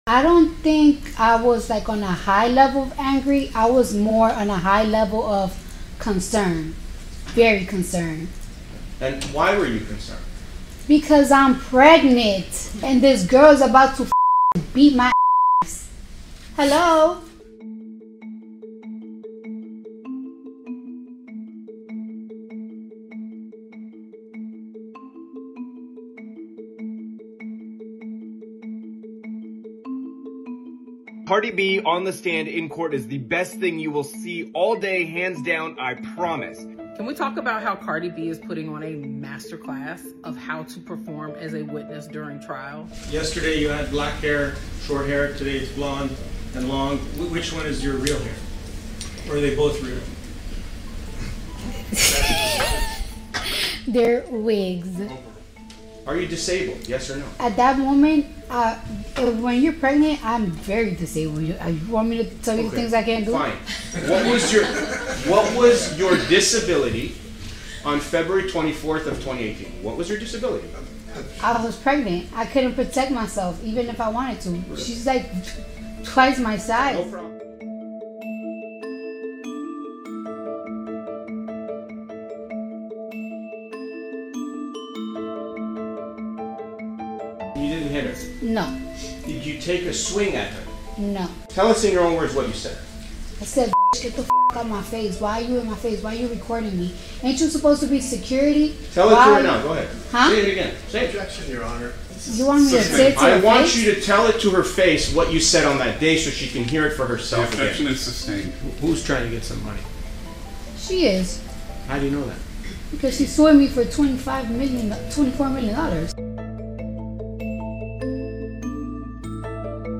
Clips from Cardi B’s courtroom sound effects free download By cnn 129 Downloads 10 hours ago 122 seconds cnn Sound Effects About Clips from Cardi B’s courtroom Mp3 Sound Effect Clips from Cardi B’s courtroom testimony, which has been live-streamed by Court TV and recirculated across social media, brought new attention to the 7-year-old case.